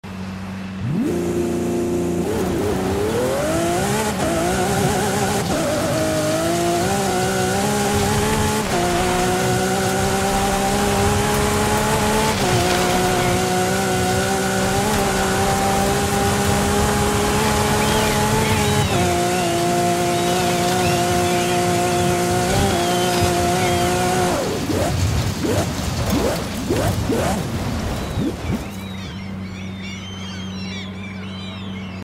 2021 Lamborghini Aventador LP 780 4 sound effects free download
2021 Lamborghini Aventador LP 780-4 Ultimae Off-Road Launch Control - Forza Horizon 5